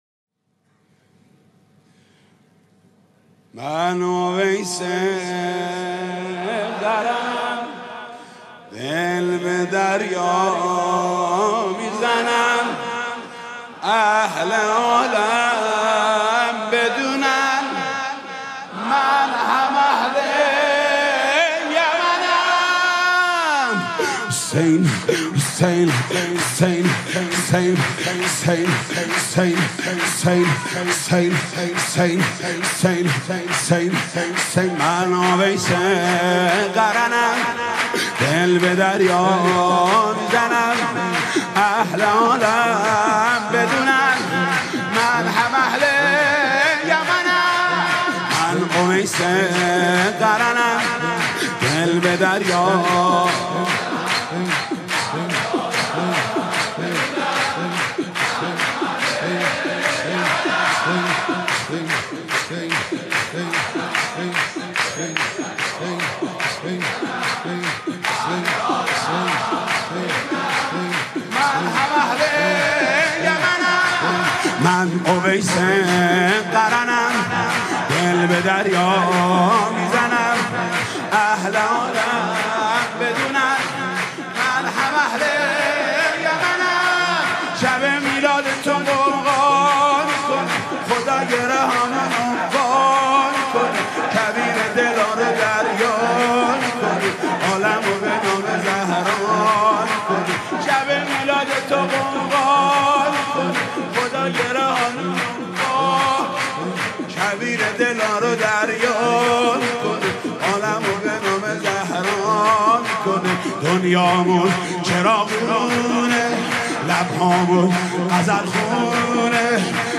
سرود: من اویس قرنم، دل به دریا میزنم